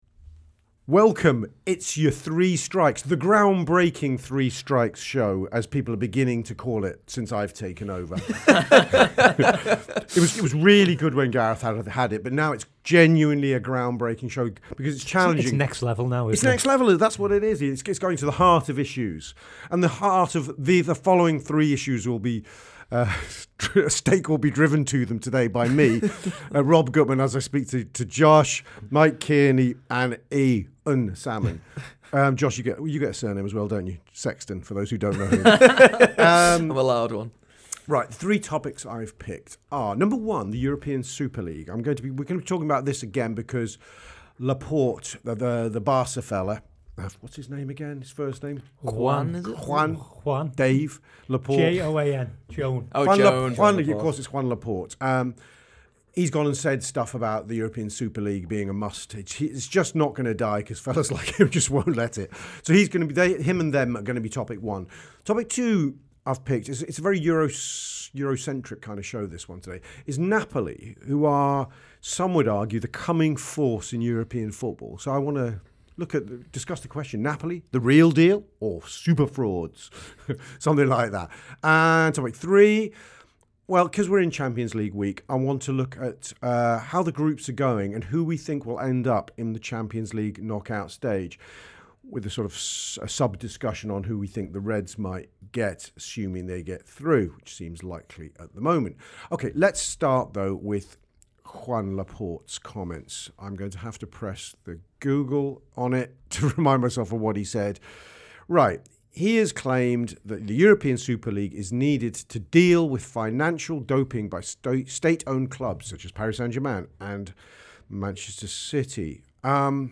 Below is a clip from the show – subscribe for more on the Super League and the Champions League…